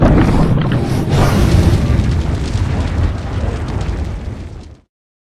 firebreath1.ogg